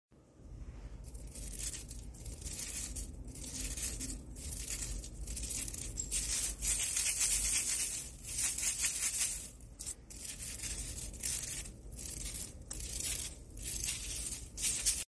im obsessed with the sound of scalemail